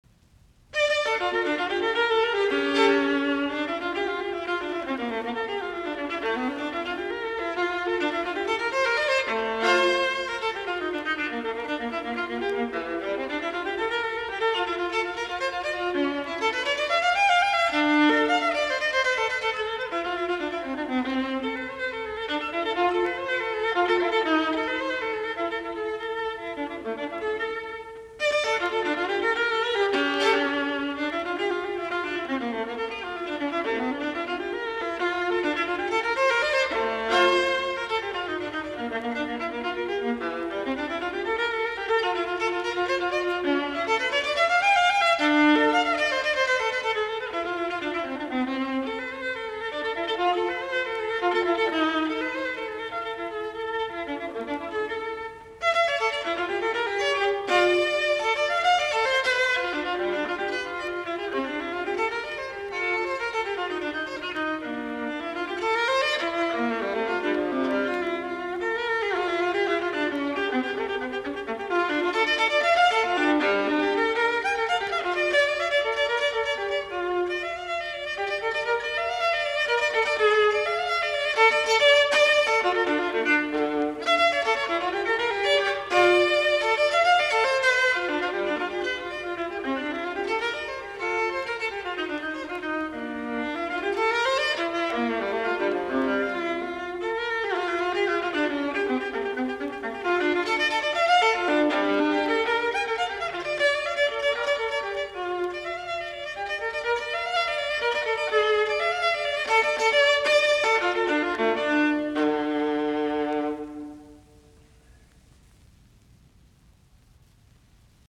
Soitinnus: Alttoviulu.